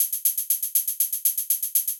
120_HH_2.wav